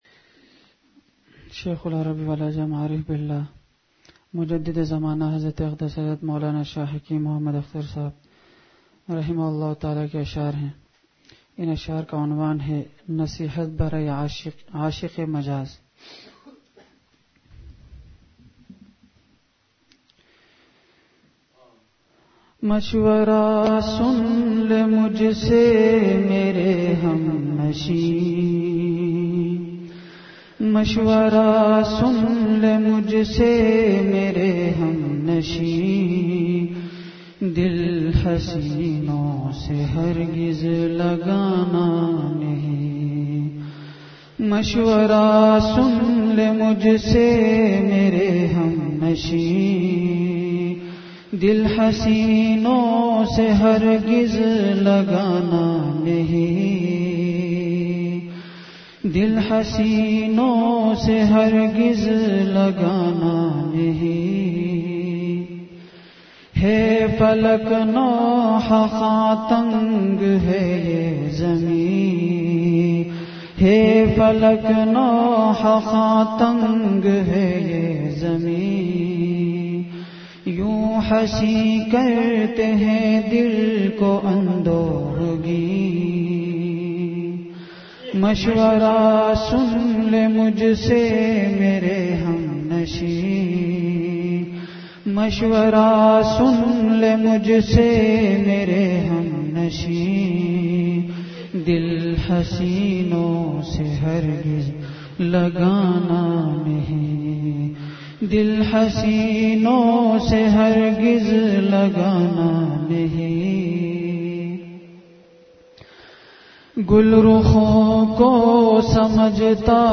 مجلس۲۱دسمبر ۲۰۱۵ء:مجلسِ اشعار!